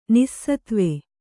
♪ nissatve